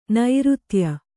♪ nairutya